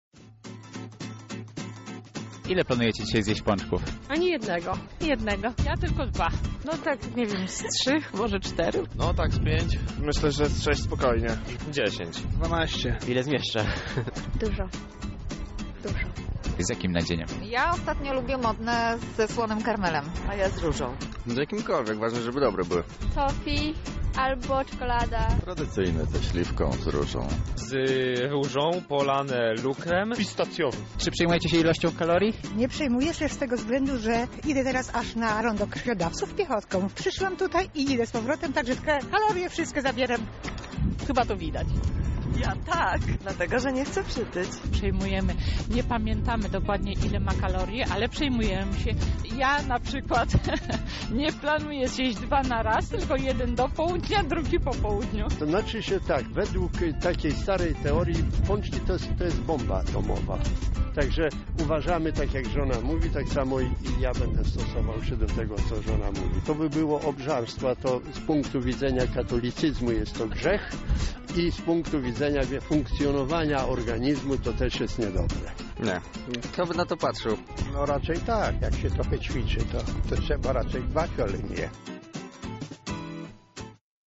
Sonda pączki